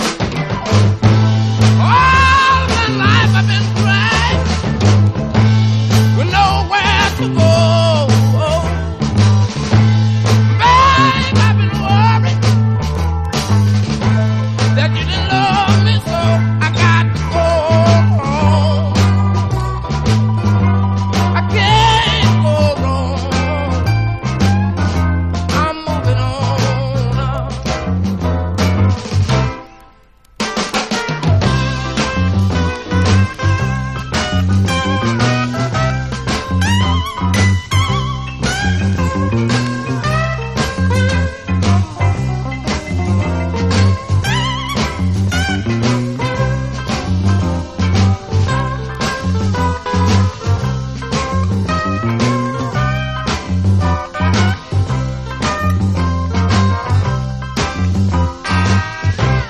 SOUL / SOUL / 60'S / BLUES